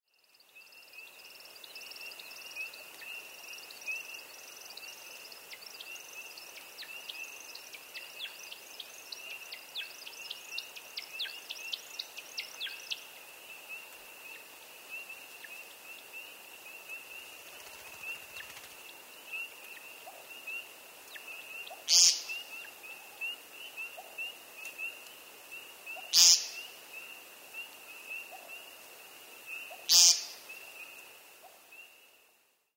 American Woodcock
BIRD CALL: DISTINCT “PEENT” CALL GIVEN AS PART OF A DRAMATIC COURTSHIP DISPLAY AT DAWN AND DUSK IN EARLY SPRING.
American-woodcock-call.mp3